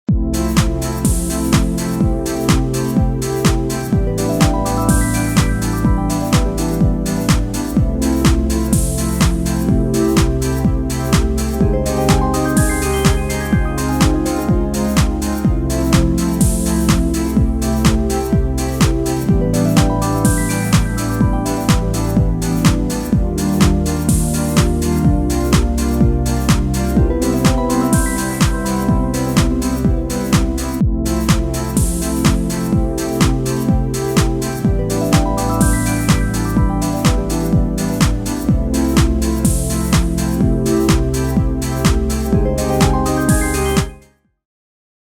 Помогите найти похожий синт/лид